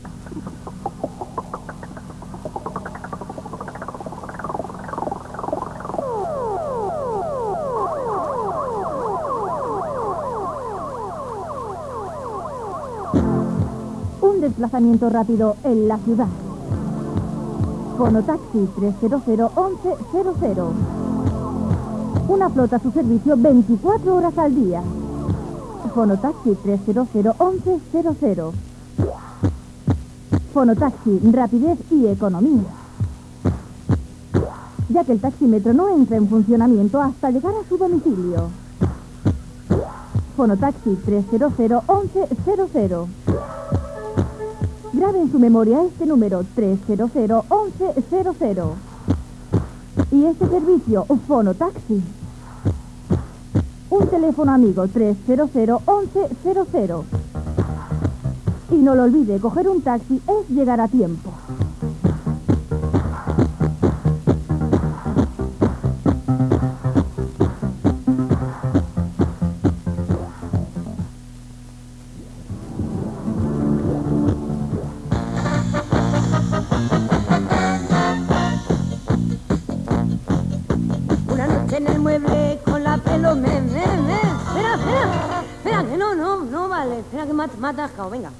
40b10ad46783efc8f474b1e28fff1d578ee91a41.mp3 Títol Fono Taxi Emissora Fono Taxi Titularitat Tercer sector Tercer sector Musical Descripció Publicitat i música.